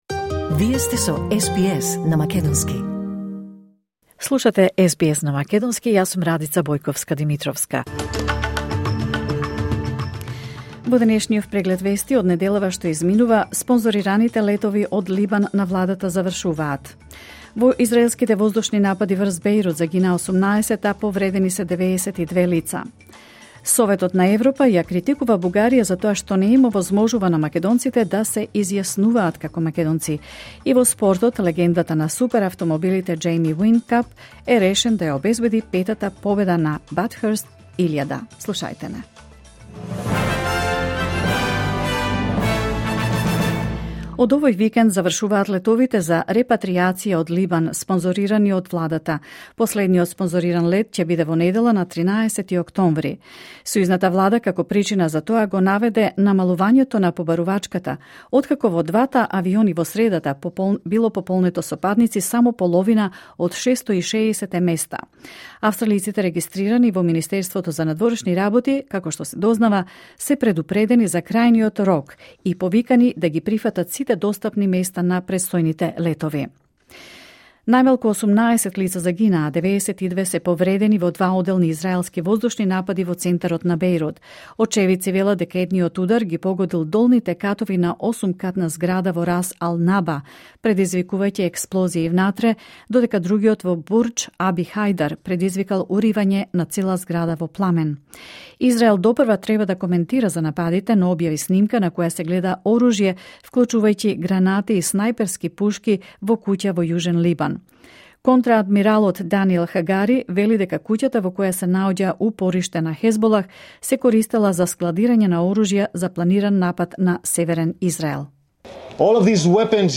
SBS News in Macedonian 11 October 2024